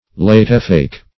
Latewake \Late"wake`\, n.